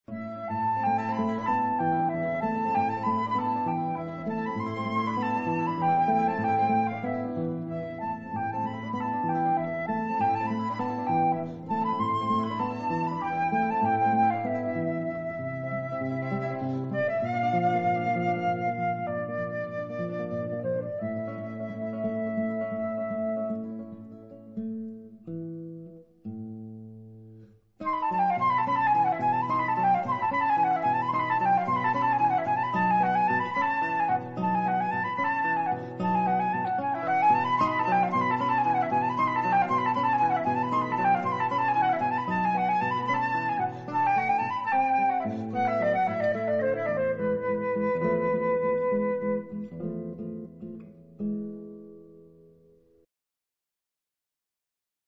flute
guitar